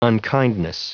Prononciation du mot unkindness en anglais (fichier audio)
Prononciation du mot : unkindness